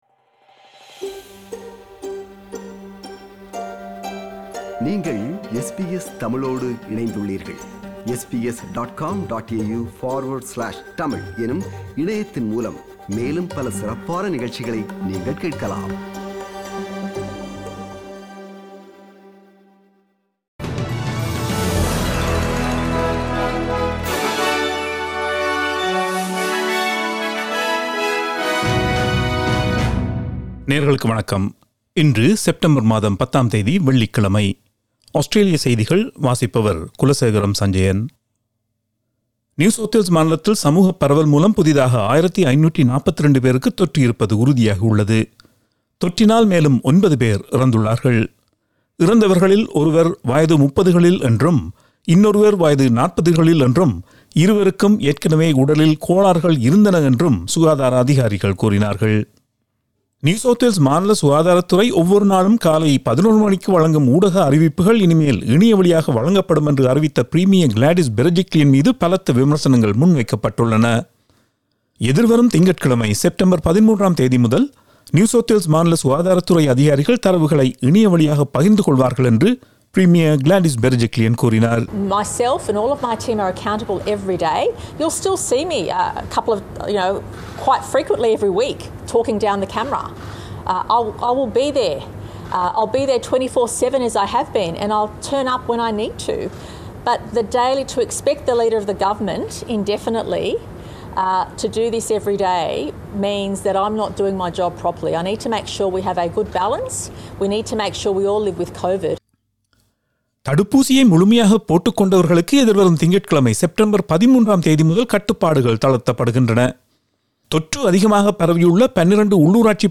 Australian news bulletin for Friday 10 September 2021.